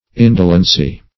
\In"do*len*cy\
indolency.mp3